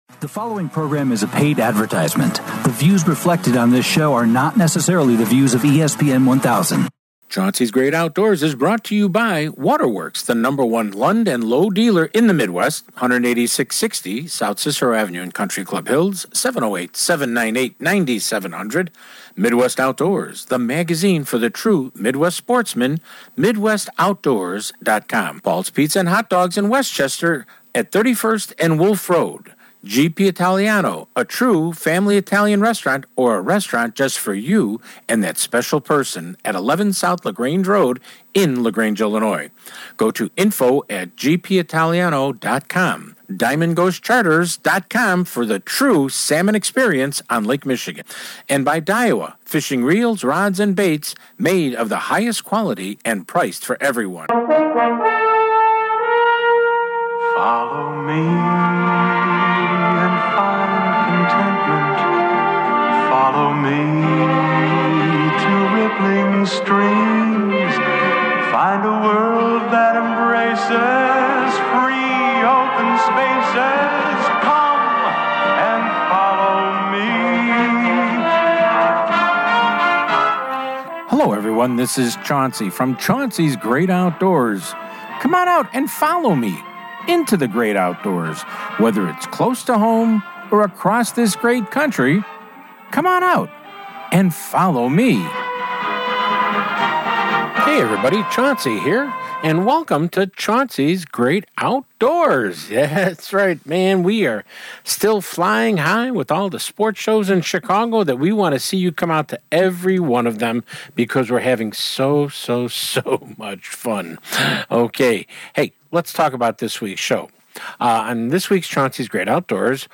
We are coming to you from the Muskie Expo at the Kane County Fairgrounds on Randall Rd just north of Rt.38 Jan 17-19.